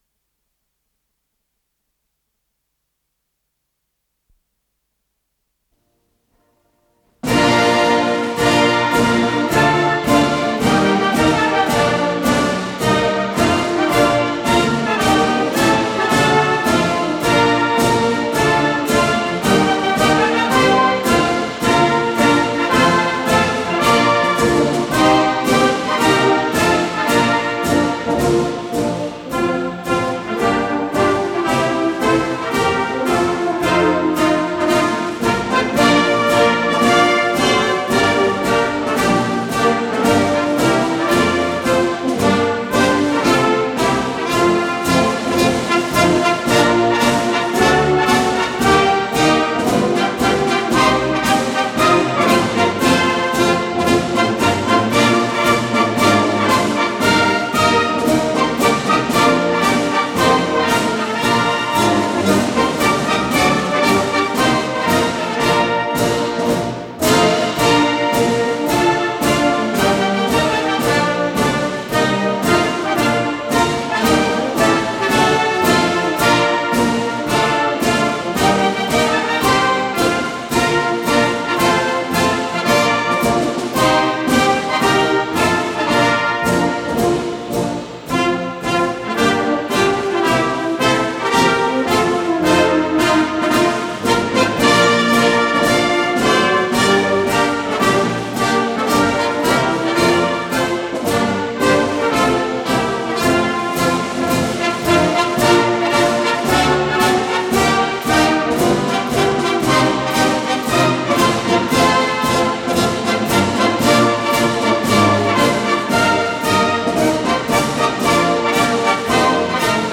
Марш-песня, си бемоль мажор